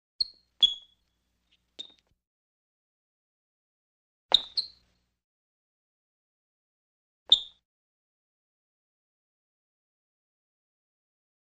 Shoe Squeaks | Sneak On The Lot
Various Tennis Shoe Squeaks On Court.